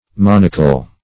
Monocle \Mon"o*cle\, n. [F. See Monocular.]